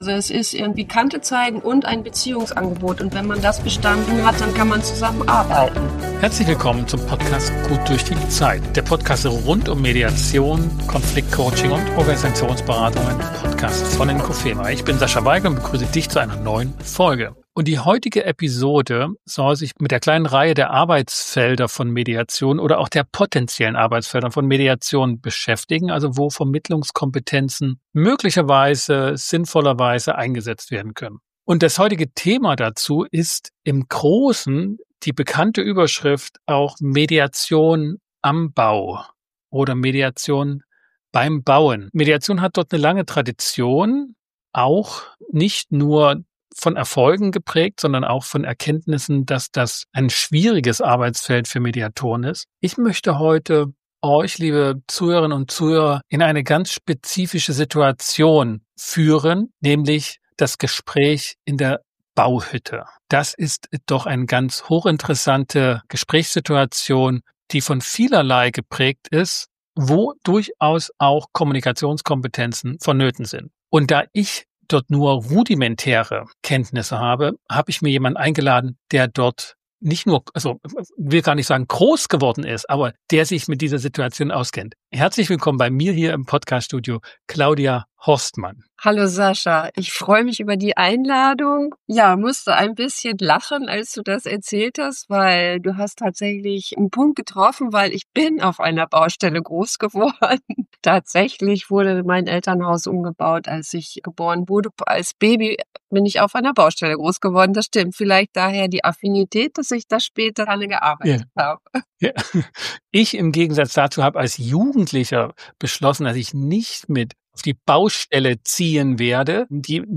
#245 GddZ - Mediation am Bau. Im Gespräch